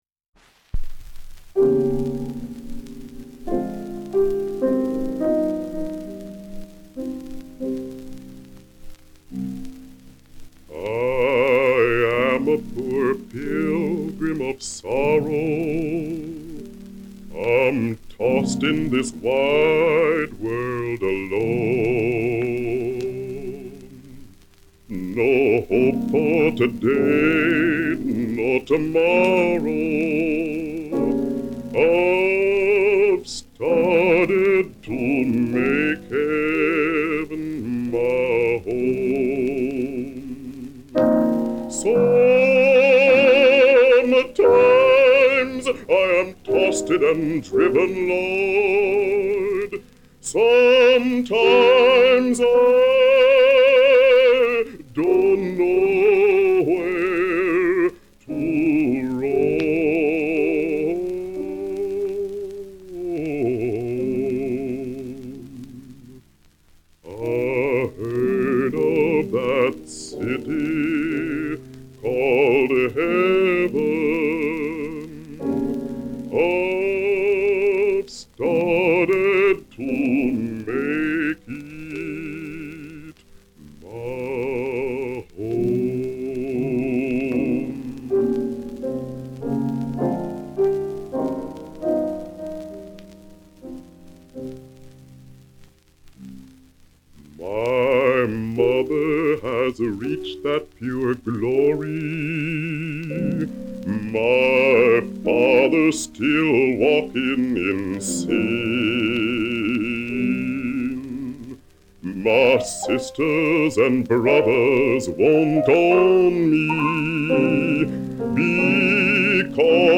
Hear Spencer perform the song “City Called Heaven” in 1946.
Kanaga photographed singer and actor Kenneth Spencer in 1933, at the very beginning of his career.